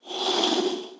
SFX_Door_Slide_07.wav